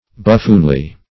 Meaning of buffoonly. buffoonly synonyms, pronunciation, spelling and more from Free Dictionary.